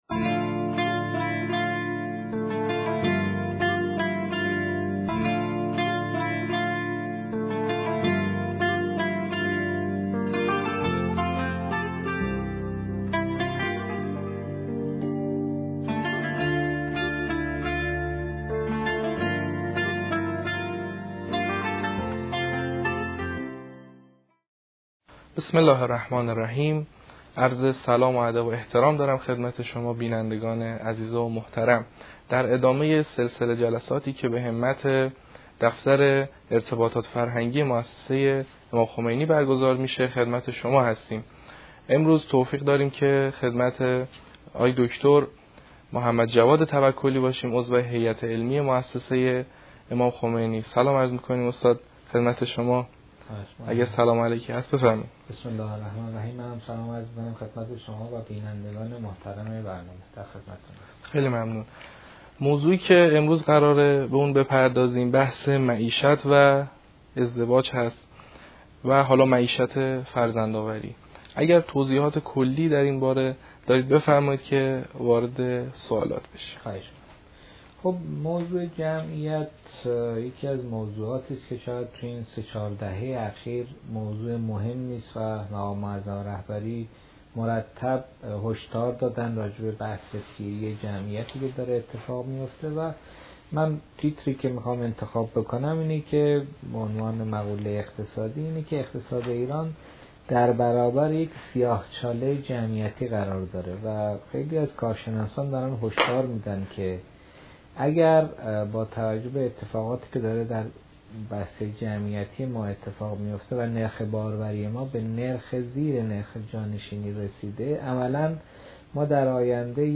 گزارش این سخنرانی را در اینجا ملاحظه فرمایید